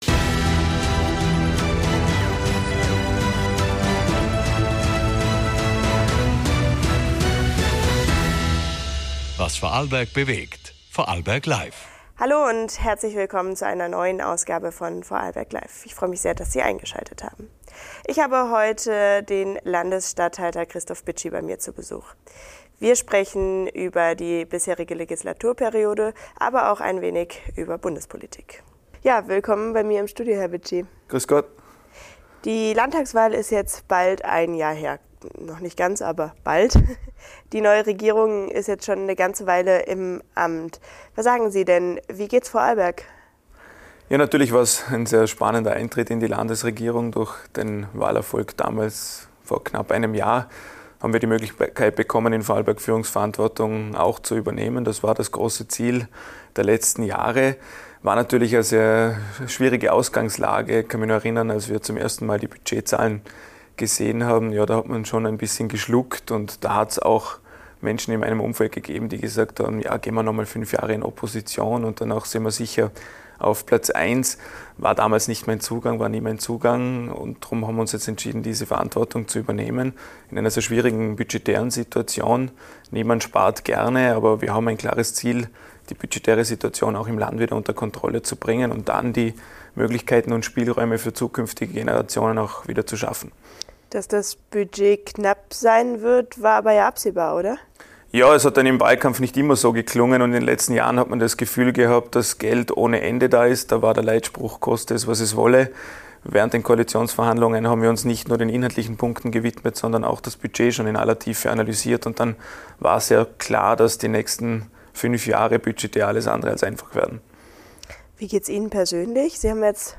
Wie geht Politik in der Krise? In dieser Folge spricht Christof Bitschi, Landesstatthalter von Vorarlberg,